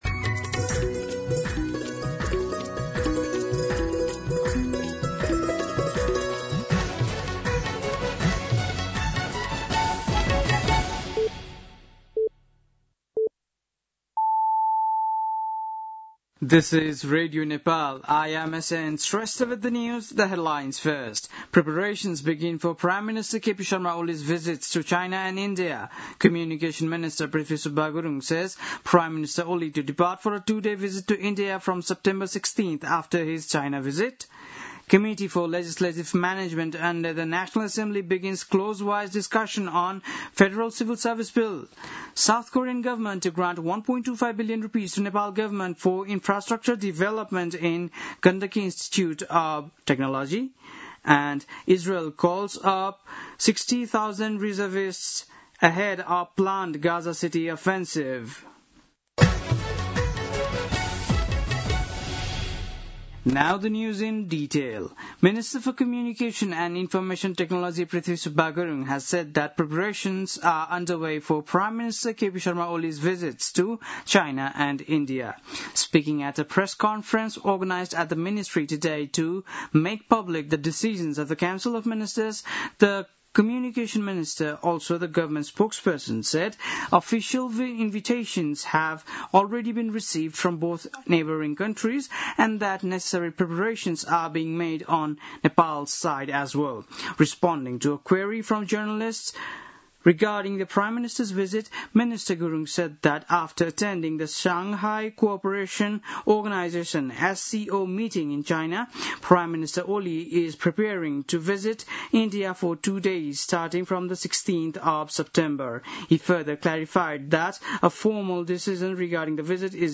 बेलुकी ८ बजेको अङ्ग्रेजी समाचार : ४ भदौ , २०८२
8-pm-news-5-4.mp3